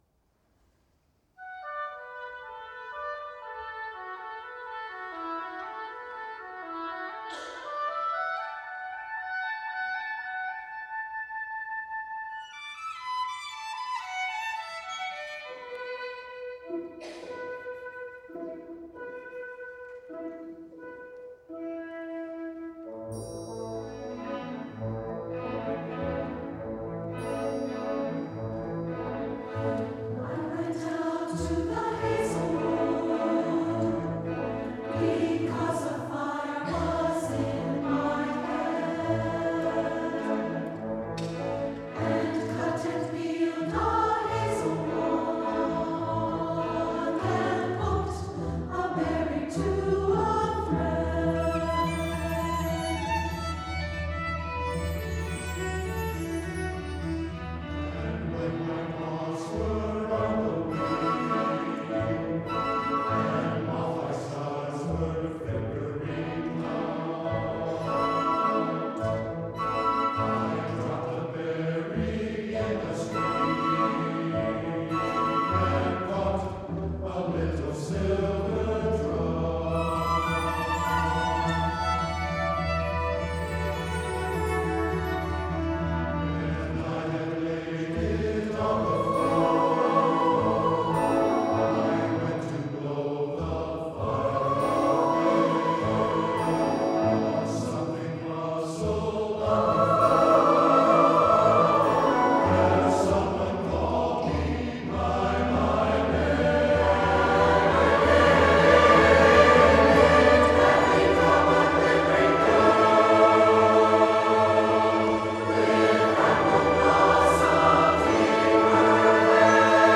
for SATB Chorus and Chamber Orchestra (2005)